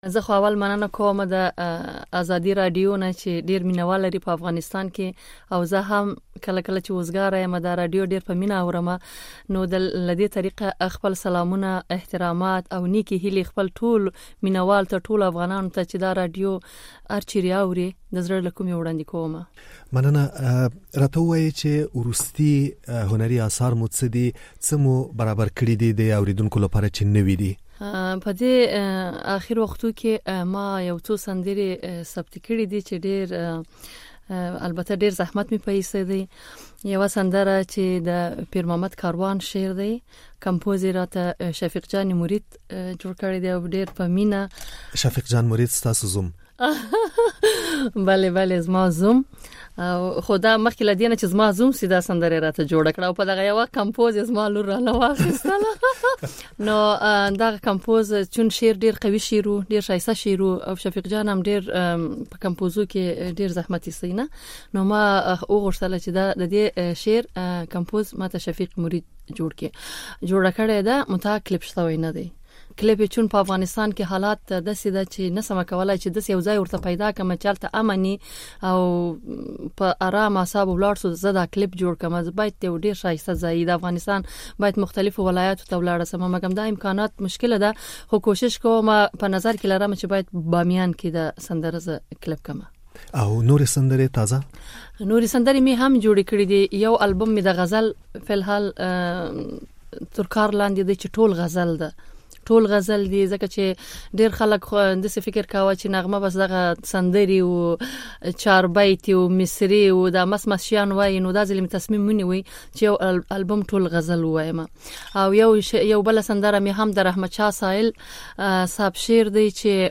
له نغمې ښاپېرۍ سره مرکه